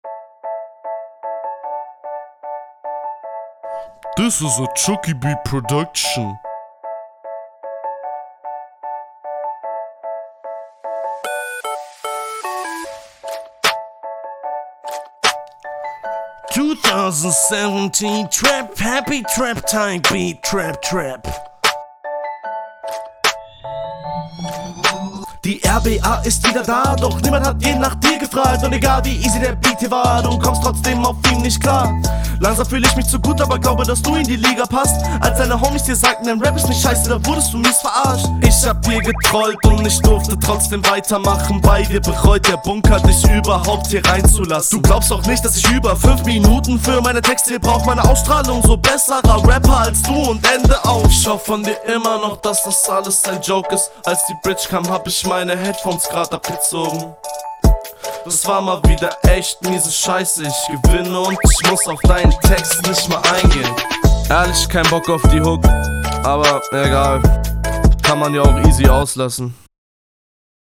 Sound um einiges besser als bei deinem Kontrahenten.
Stimmeinsatz besser.